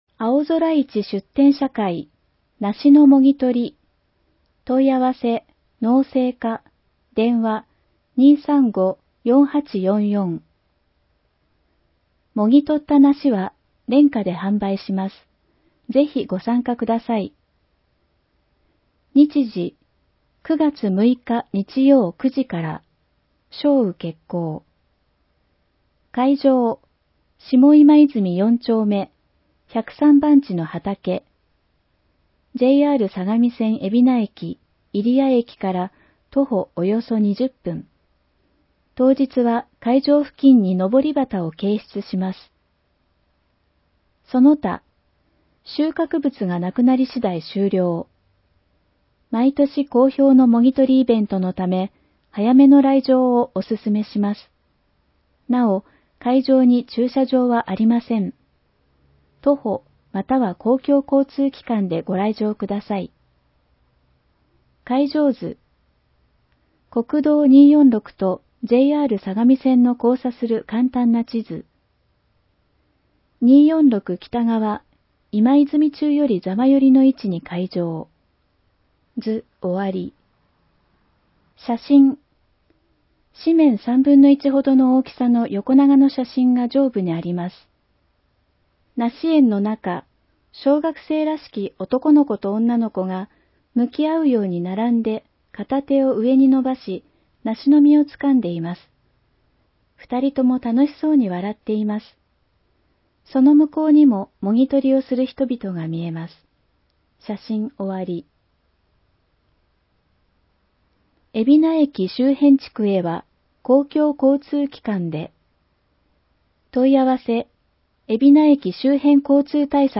広報えびな 平成27年9月1日号（電子ブック） （外部リンク） PDF・音声版 ※音声版は、音声訳ボランティア「矢ぐるまの会」の協力により、同会が視覚障がい者の方のために作成したものを登載しています。